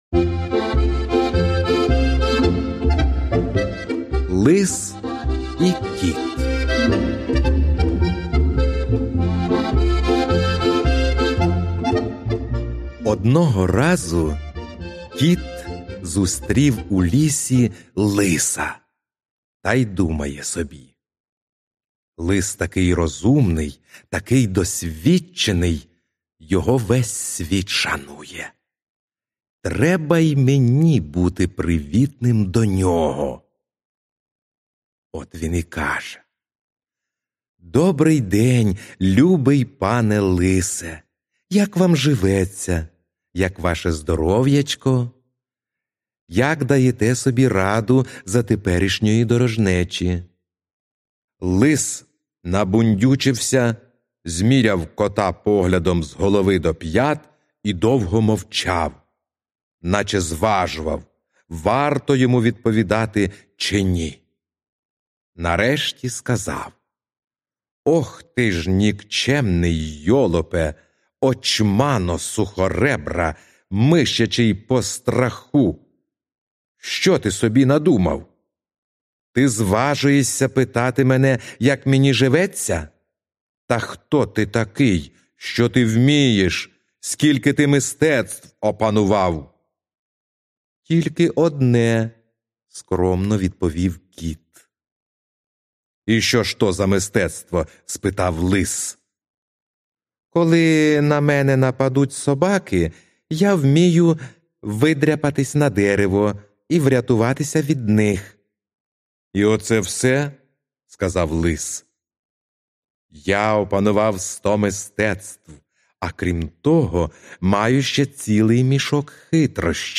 Аудіоказка Лис і кіт